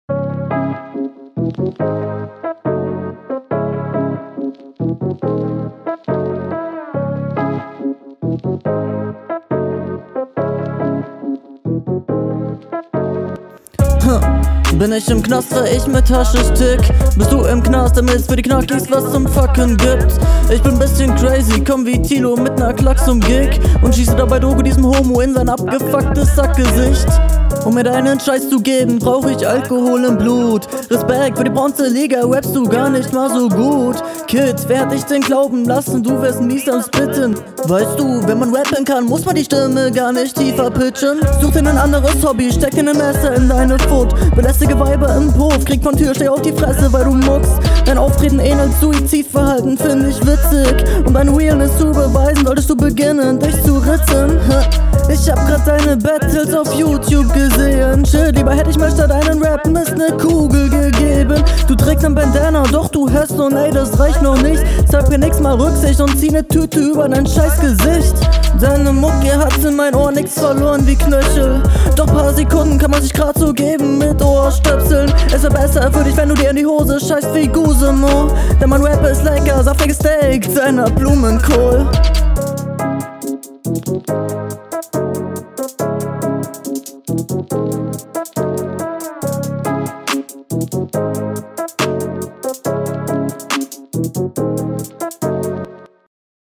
leider 90% Offbeat, da geht mehr. bin auf deine RR gespannt.